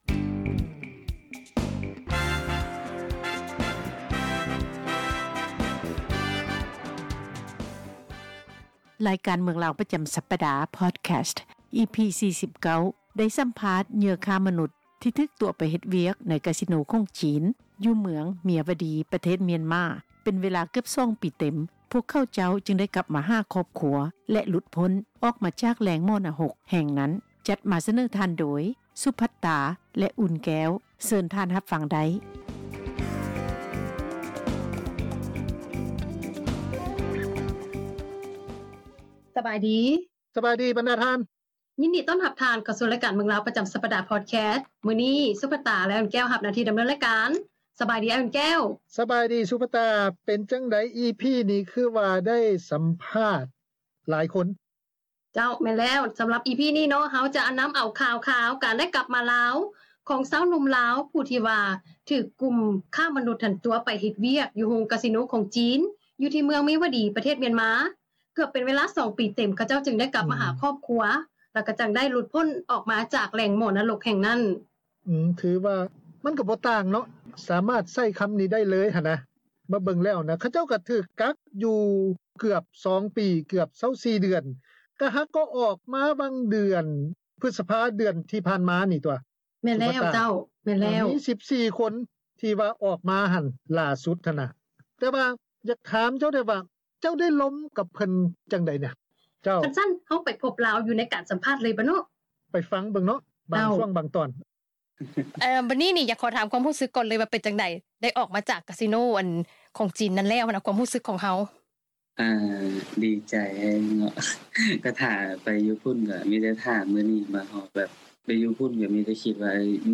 ສຳພາດ ເຫຍື່ອຄ້າມະນຸດ ທີ່ຖືກຕົວະໄປເຮັດວຽກ ໃນໂຮງກາສິໂນ ຂອງຈີນ ຢູ່ ມຽນມາ